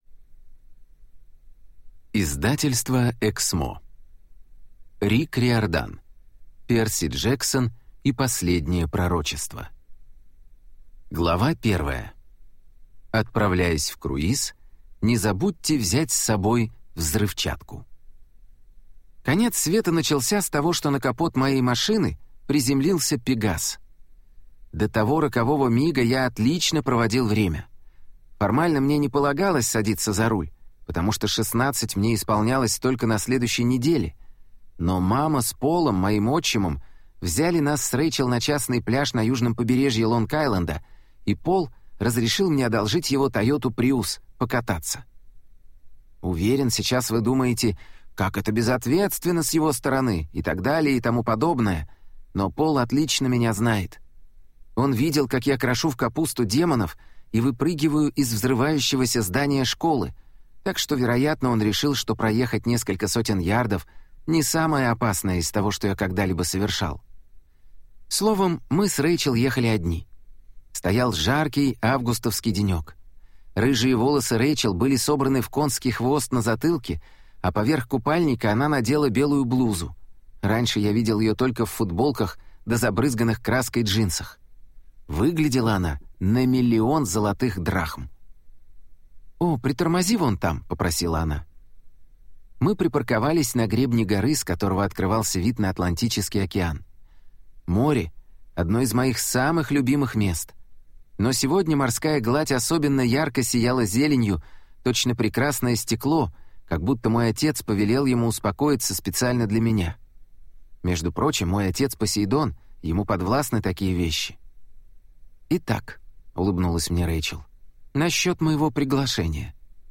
Аудиокнига Перси Джексон и последнее пророчество | Библиотека аудиокниг